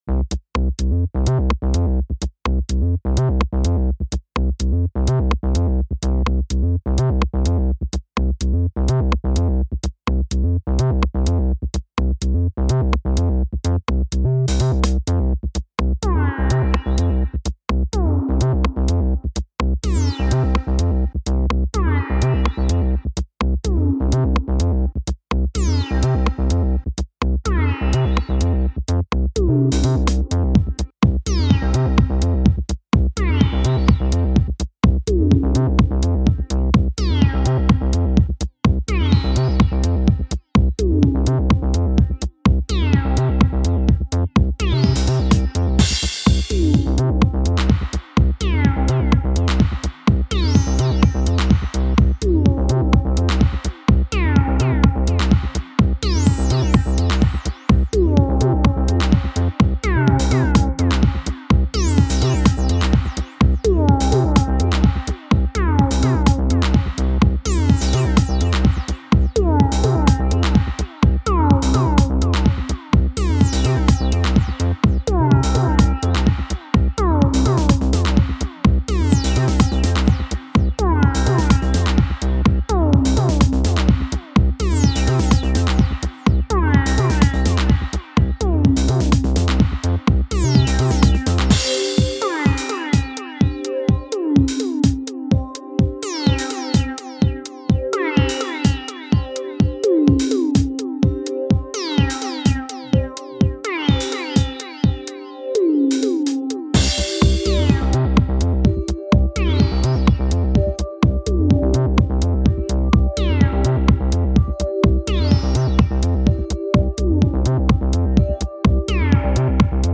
Original techno tracks.
全てPowerBook G4、1台で完結しちゃってます。外部の機材は全く使用してません。